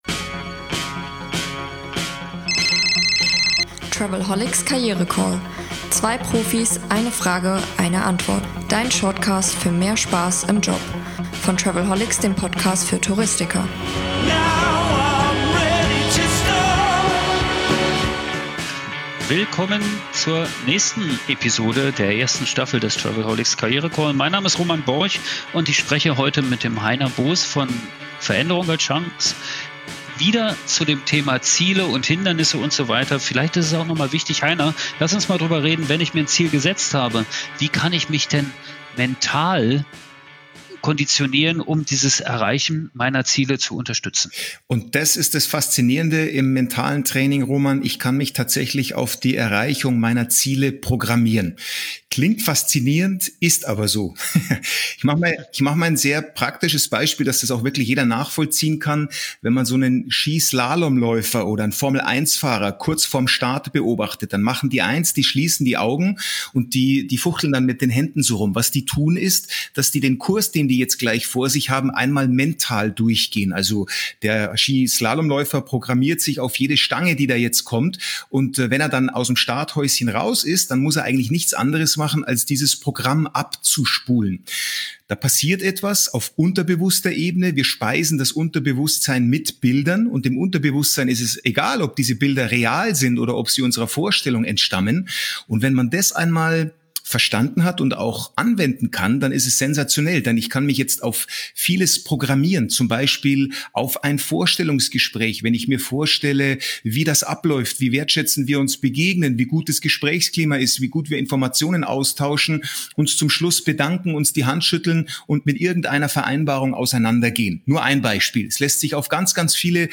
Der travelholics KarriereCALL Staffel 1: täglich - 2 Profis - 1 Frage - 1 Mic